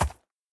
mob / horse / soft1.ogg